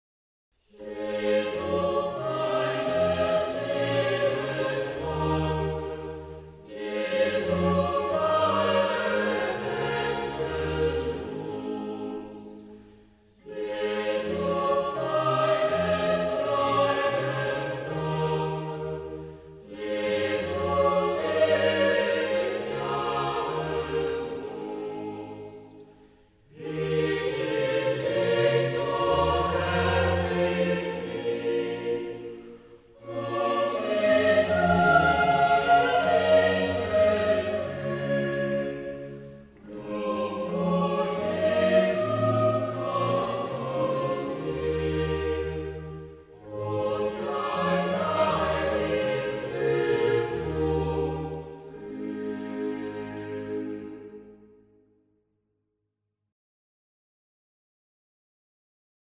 BWV0360_(WerdeMunterMeinGemüthe)(Choral)_().mp3